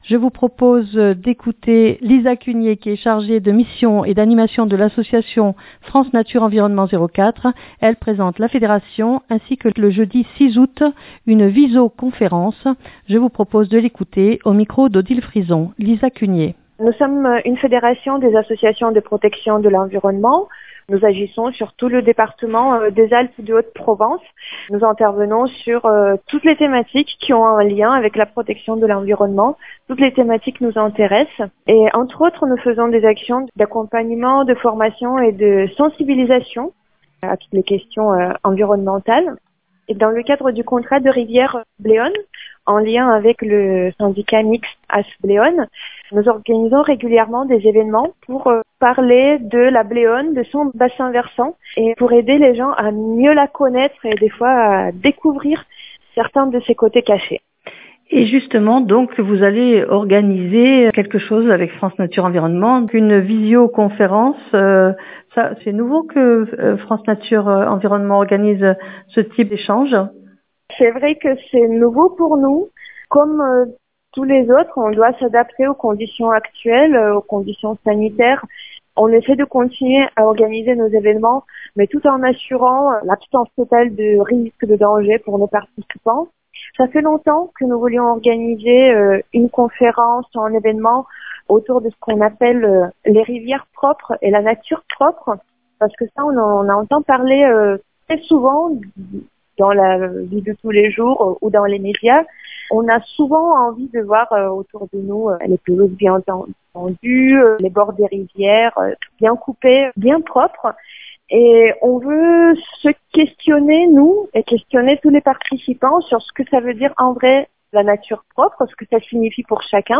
Elle présente la fédération ainsi que la 1ère vis'Eau conférence qui aura lieu Jeudi 6 août. Je vous propose de l'écouter au micro